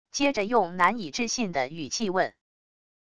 接着用难以置信的语气问wav音频